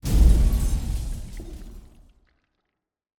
Commotion13.ogg